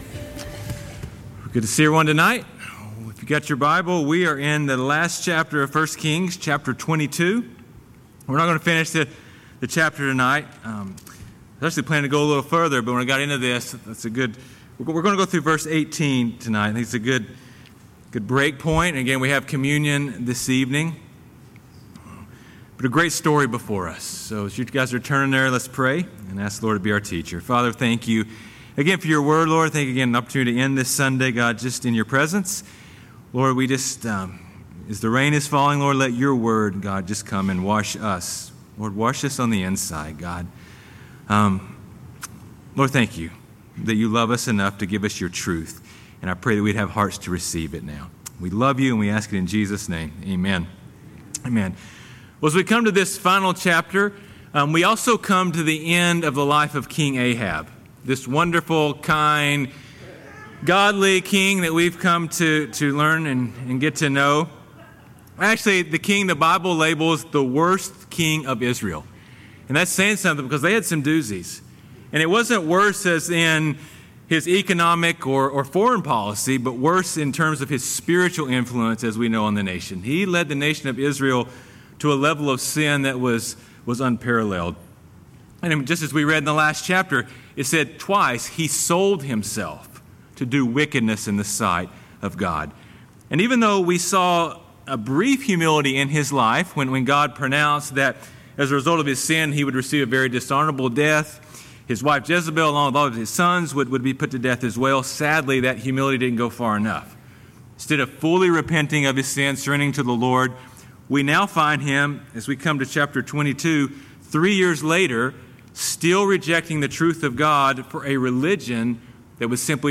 Calvary Chapel Knoxville